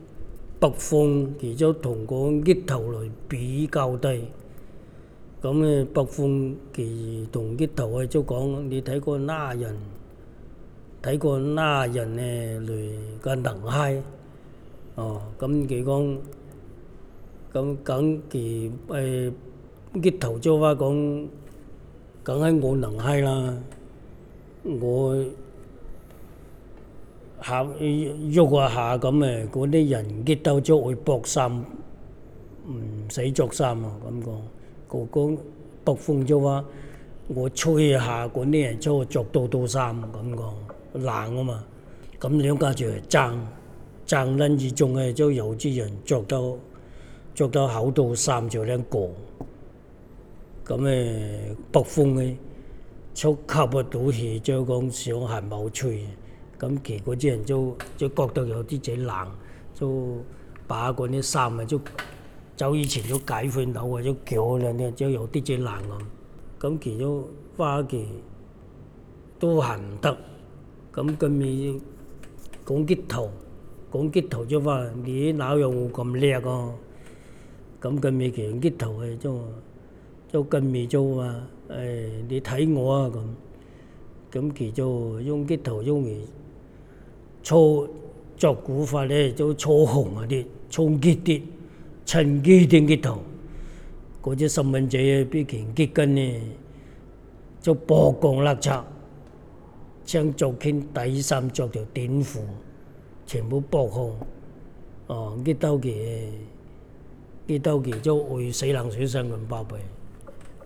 dc.formatdigital wav file recorded at 44.1 kHz/16 bit on Zoom H2 solid state recorder
Dapeng dialect in Shenzhen, China